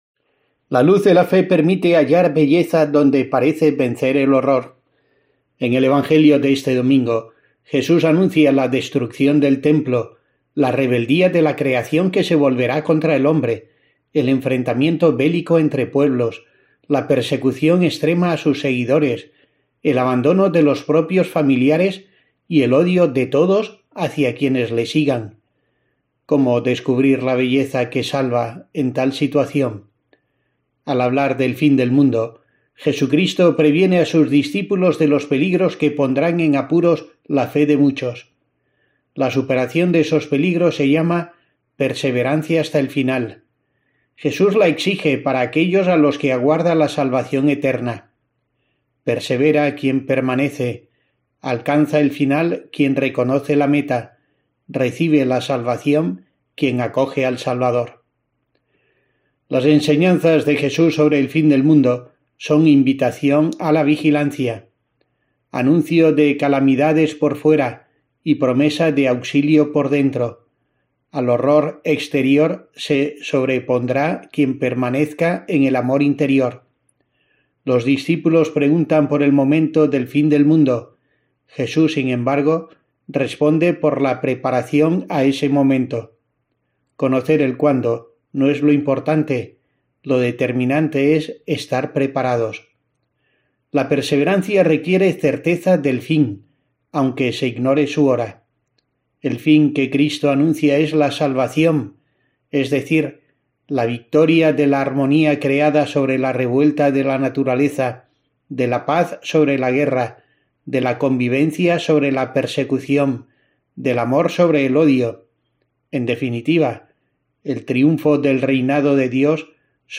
El Evangelio del domingo insta, como siempre, la reflexión del obispo de Asidonia-Jerez; esta vez sobre la actitud ante la guerra, el fin del mundo, el pecado...